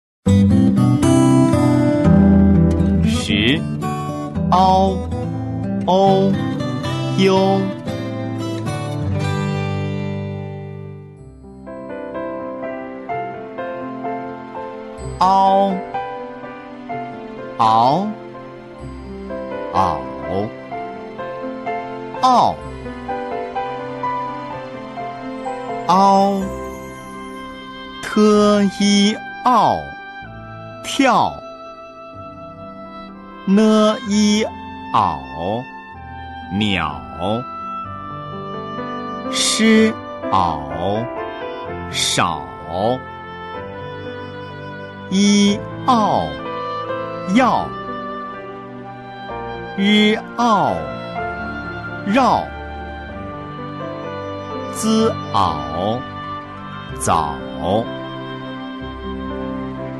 10 ao ou iu 朗读 音视频素材